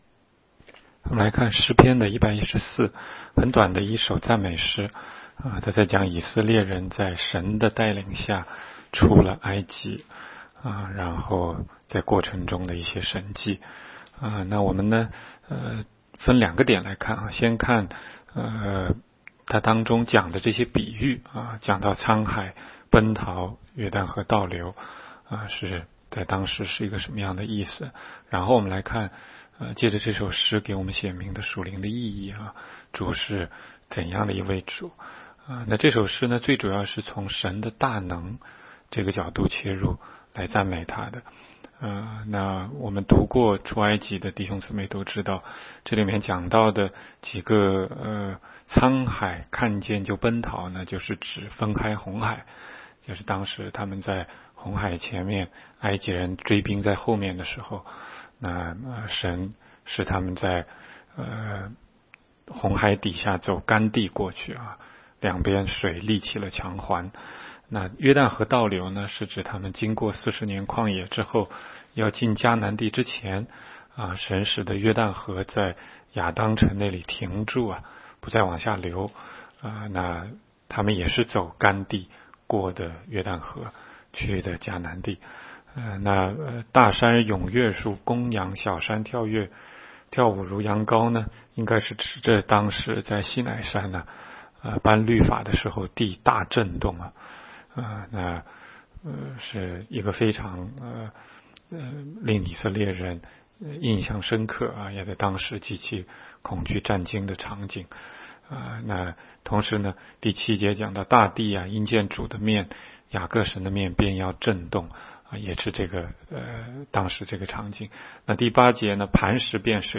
16街讲道录音 - 每日读经 -《 诗篇》114章